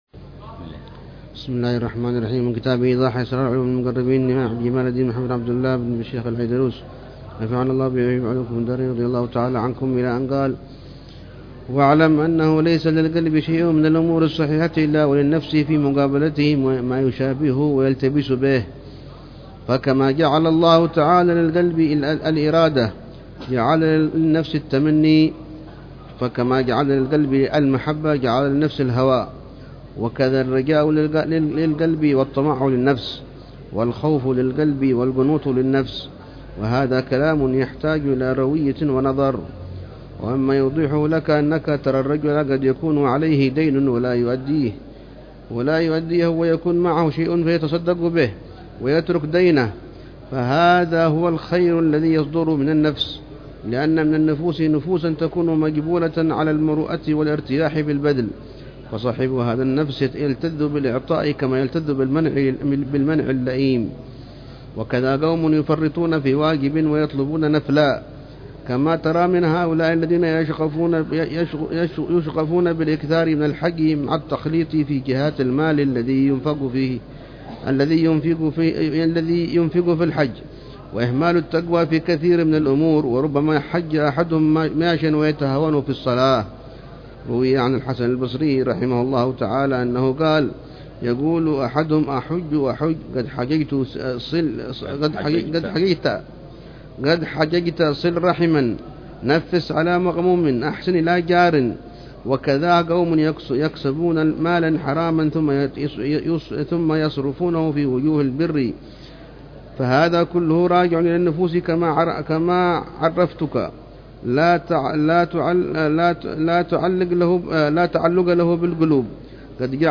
شرح للحبيب عمر بن حفيظ على كتاب إيضاح أسرار علوم المقربين للإمام محمد بن عبد الله بن شيخ العيدروس، الذي اهتم بتوضيح معالم طريق السالكين والعبا